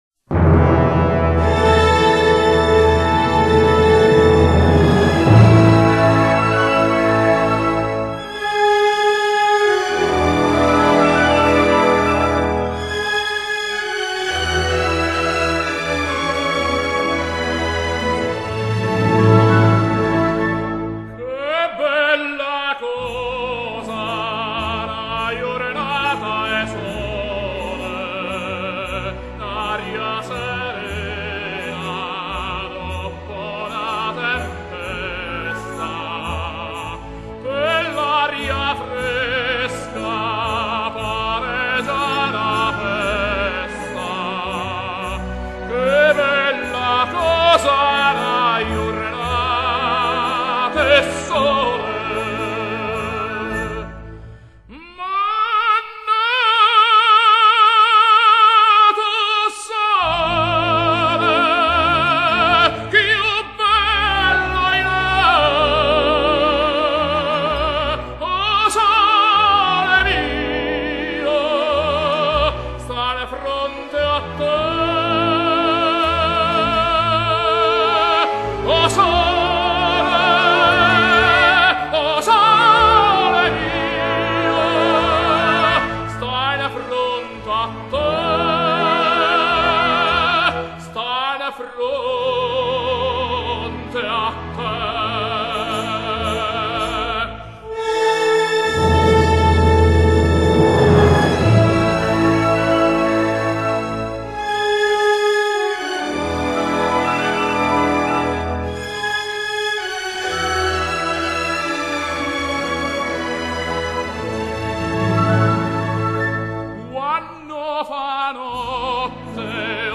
Genre: Classical Pop